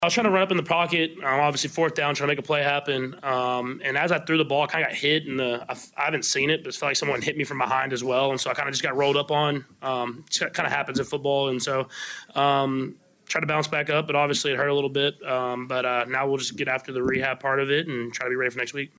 KC Patrick Mahomes describes the play in which he suffered an ankle injury that led to him leaving the game late in the fourth quarter.